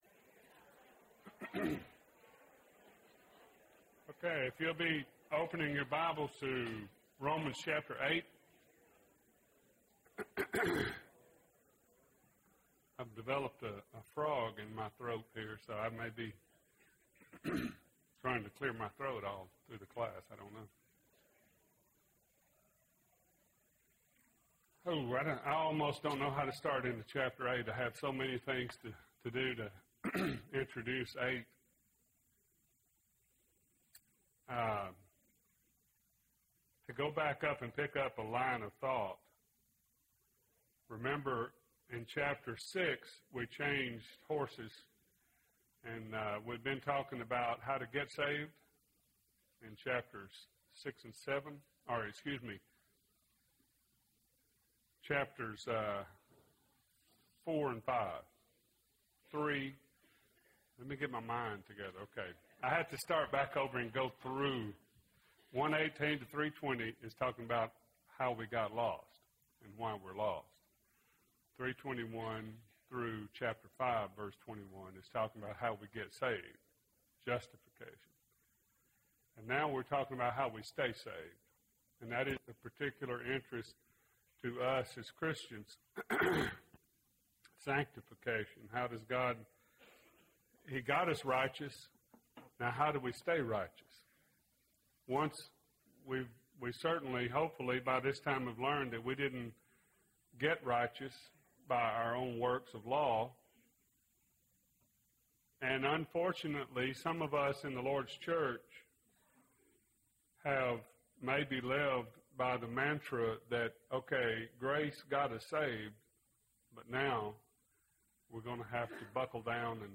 Romans – No Condemnation (18 of 24) – Bible Lesson Recording
Sunday AM Bible Class